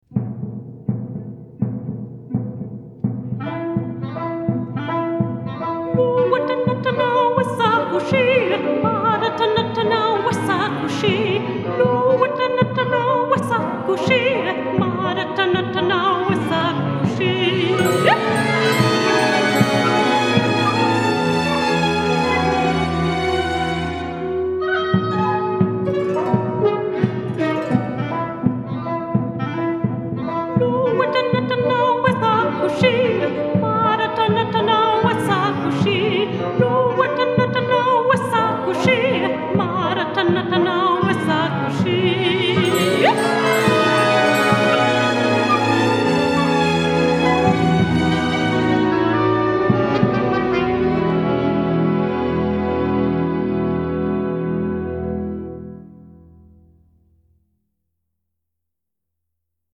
Orquesta
Música vocal